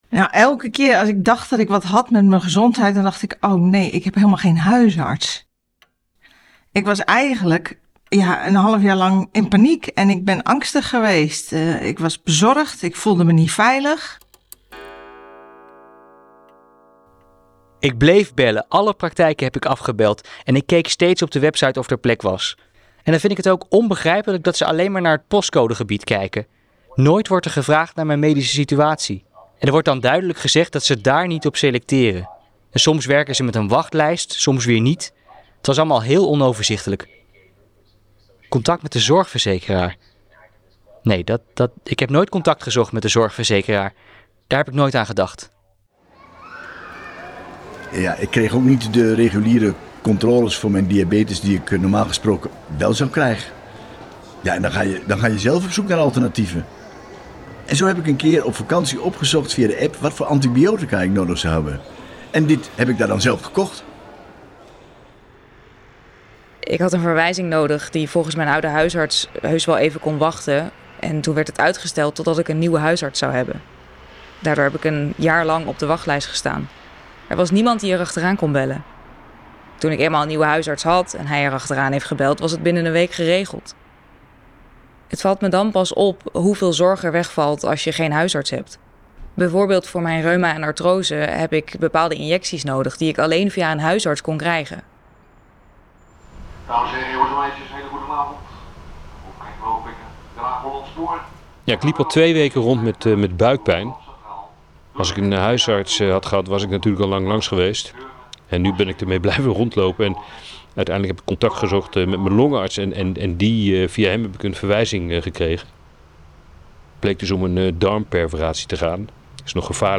Meerdere mensen zonder huisarts delen hun ervaring (ingesproken door acteurs)